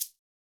KIN Shaker 1.wav